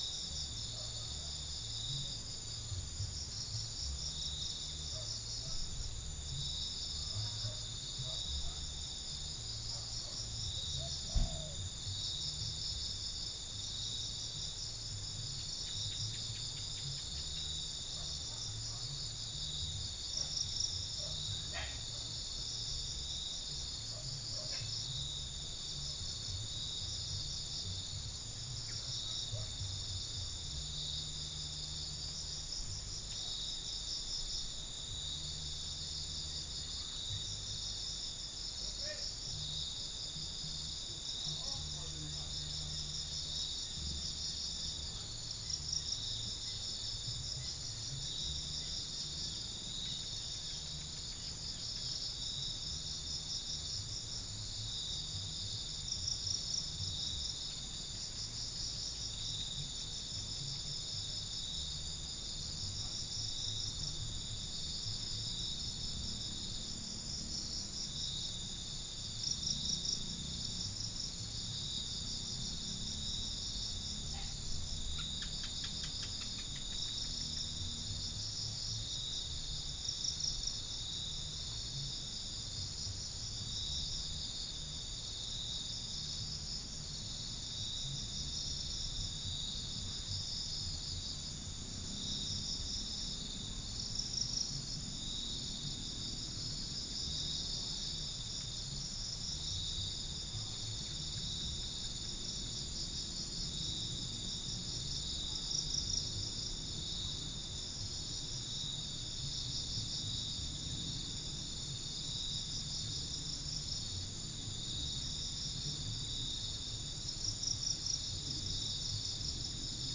Spilopelia chinensis
Todiramphus chloris